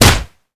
kick_heavy_impact_07.wav